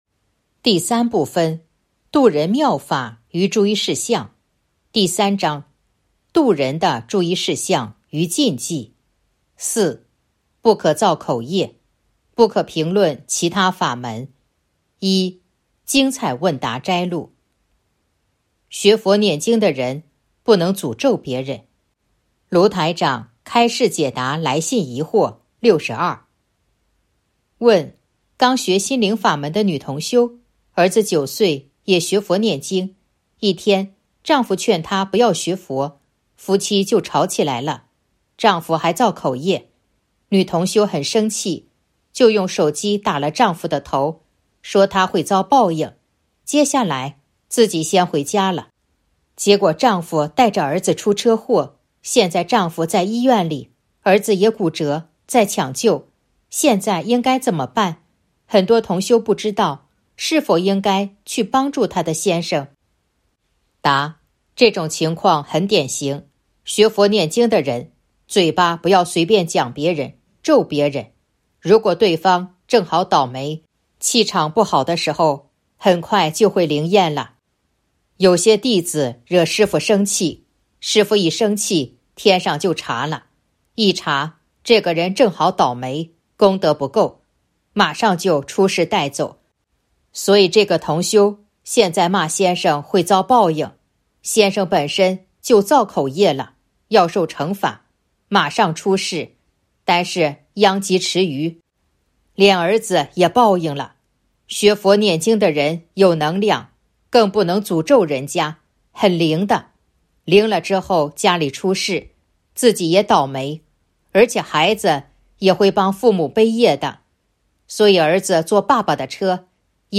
056.1. 精彩问答摘录《弘法度人手册》【有声书】